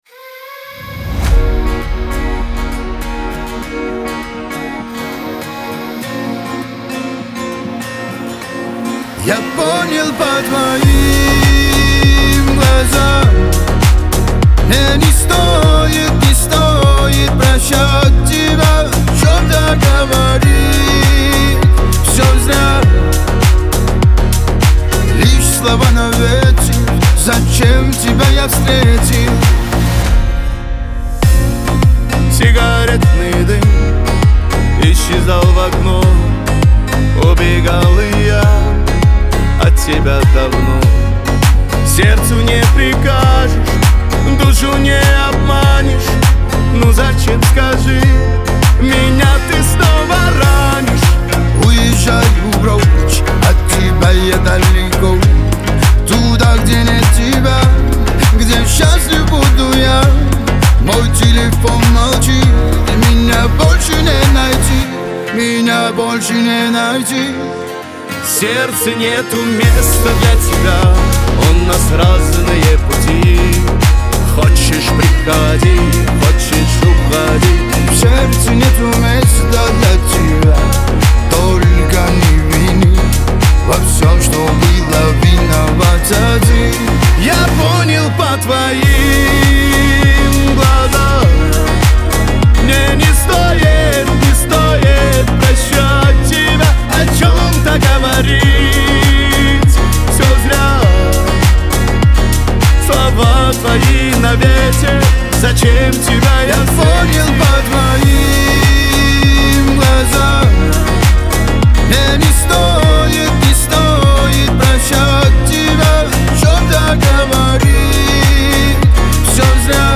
дуэт
Кавказ – поп
Лирика